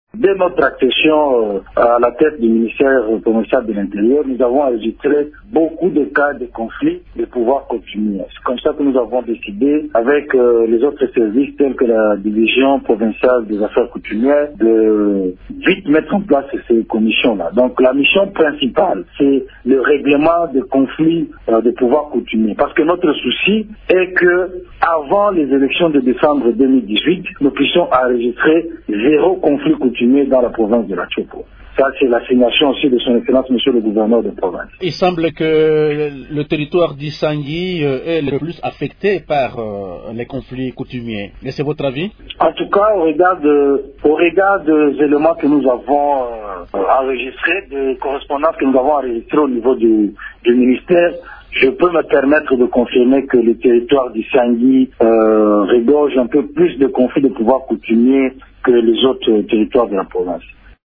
Le territoire d’Isangi compte plus de conflits coutumier que les autres, a indiqué le ministre provincial Akamba, dans cet extrait sonore: